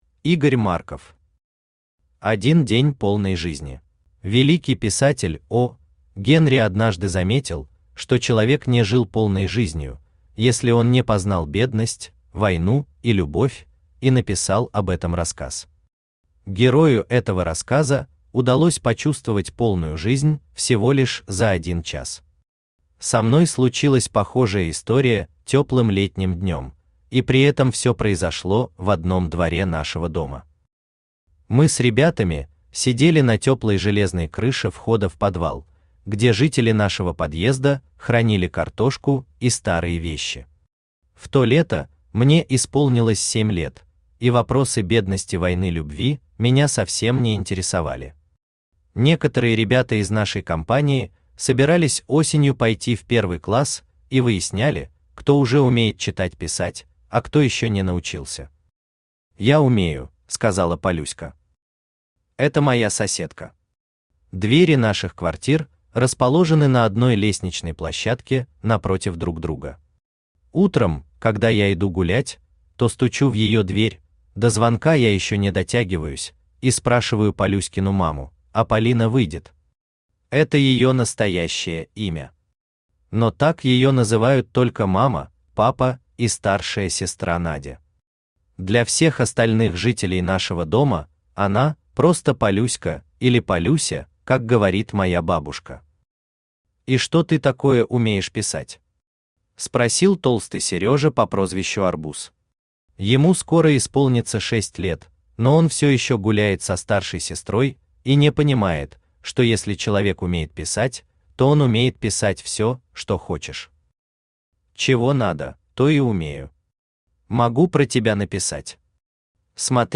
Аудиокнига Один день полной жизни | Библиотека аудиокниг
Aудиокнига Один день полной жизни Автор Игорь Владимирович Марков Читает аудиокнигу Авточтец ЛитРес.